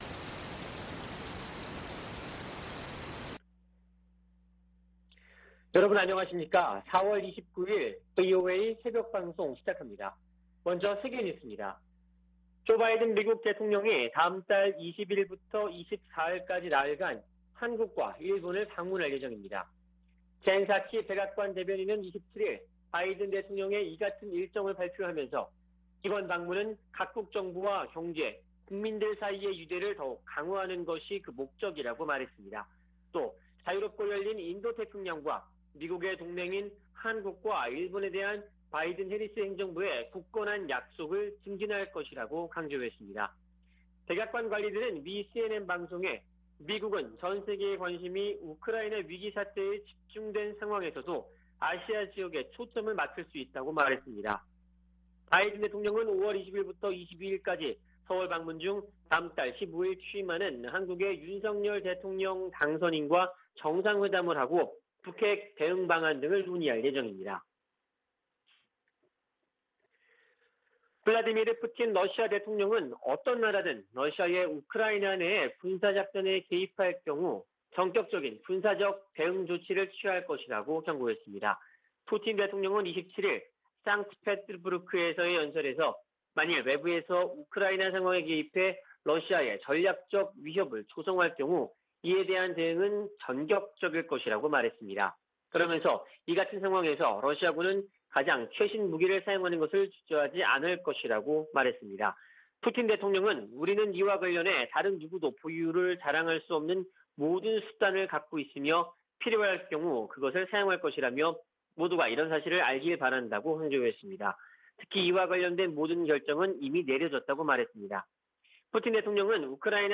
VOA 한국어 '출발 뉴스 쇼', 2022년 4월 29일 방송입니다. 조 바이든 대통령이 다음 달 20일부터 24일까지 한국과 일본을 방문합니다.